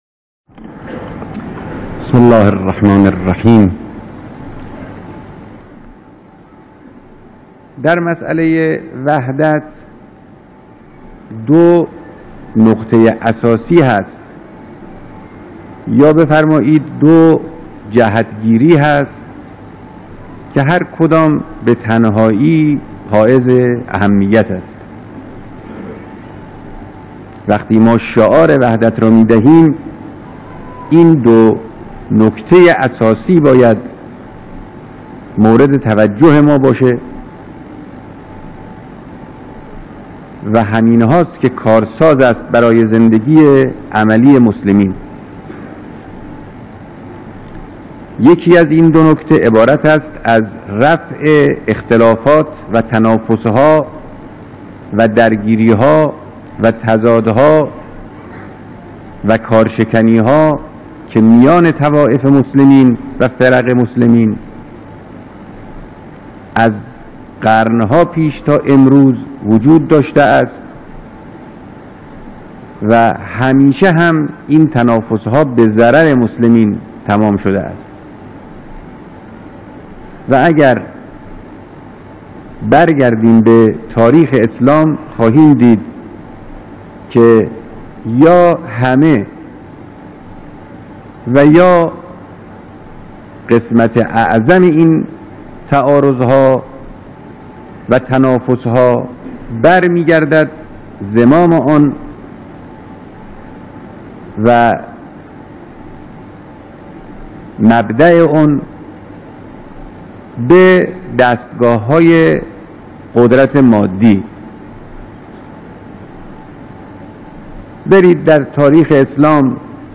بیانات رهبر انقلاب در دیدار مسئولان نظام و میهمانان کنفرانس وحدت اسلامی